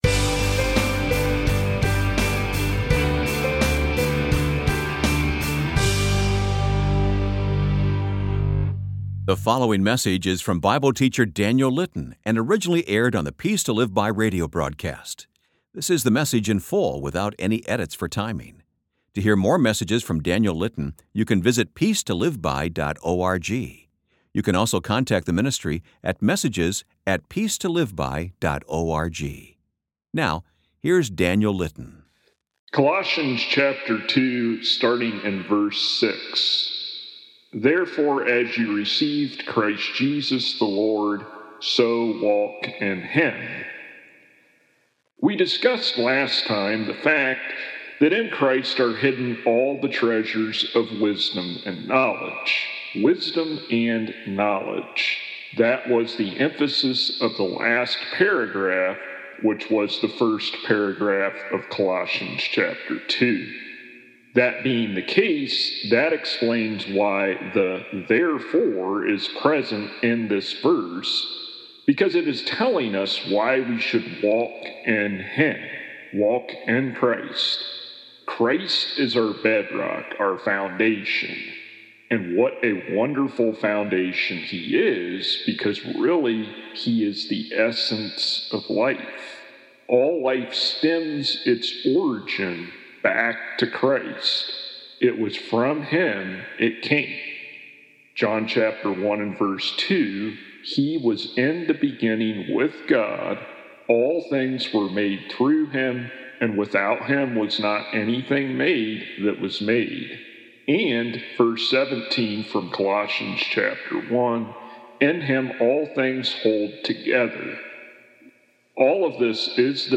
Colossians Musings Full Sermons